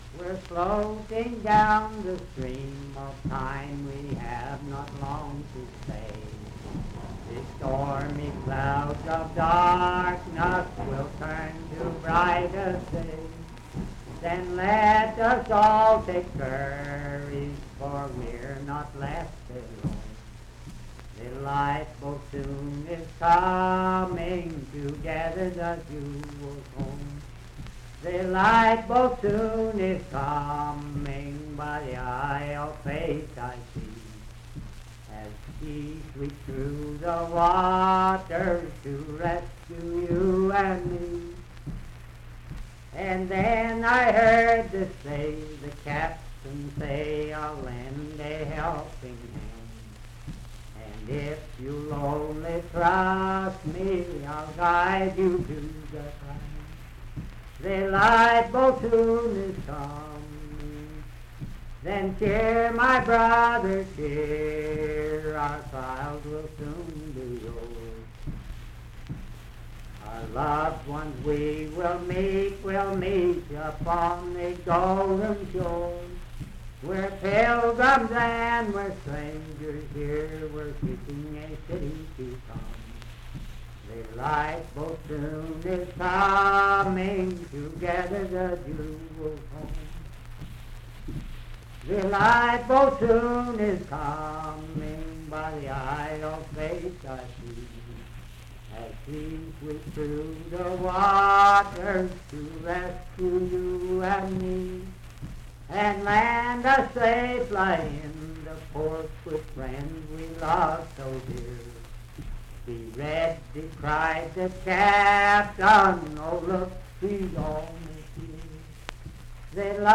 Unaccompanied vocal music and folktales
Hymns and Spiritual Music
Voice (sung)
Wood County (W. Va.), Parkersburg (W. Va.)